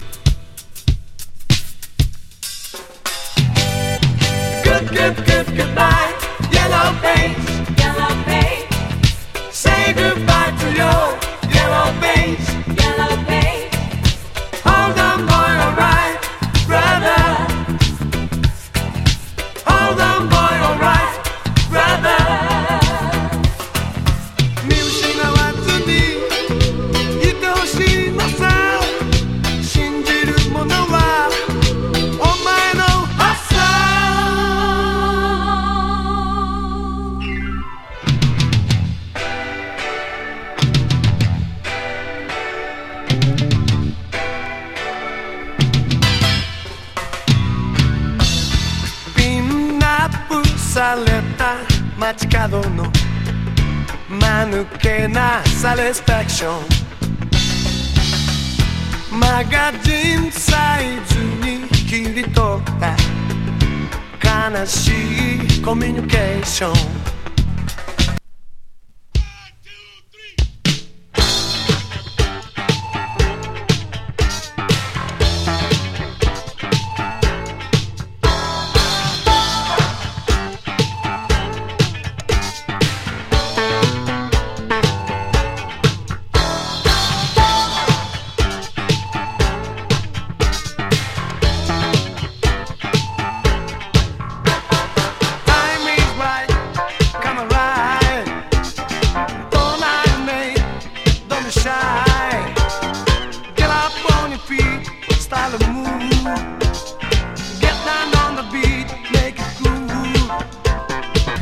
関西レゲー
ディスコティーク